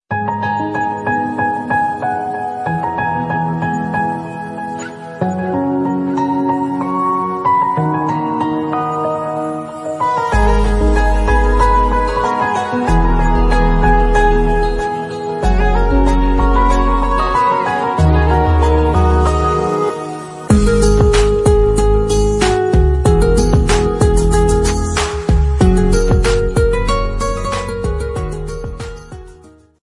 Genres: Acoustic